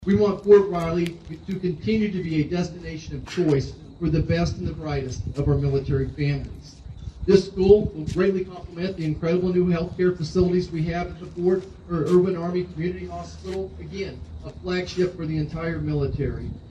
Geary County Schools USD 475 hosted a ribbon cutting and dedication ceremony for the 437,000 square foot facility.